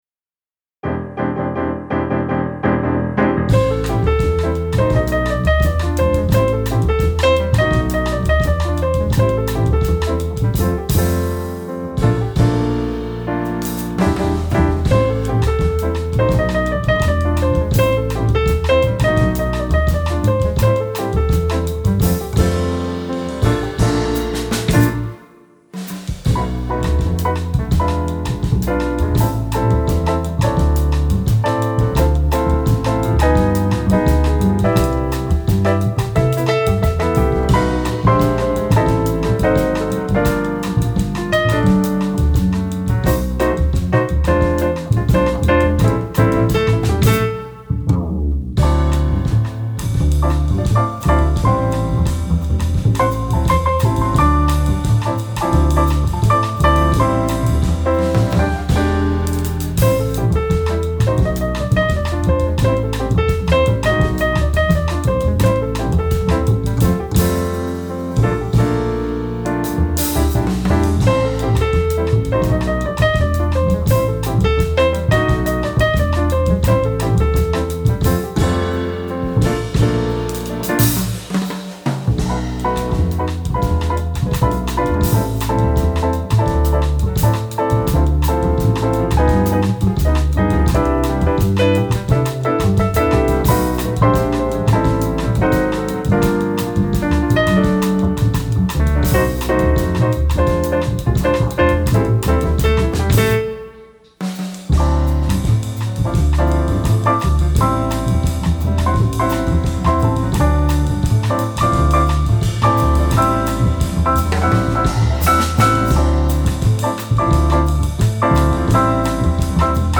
Никаких "расширялок", дублей и правок на треках - их не трогал.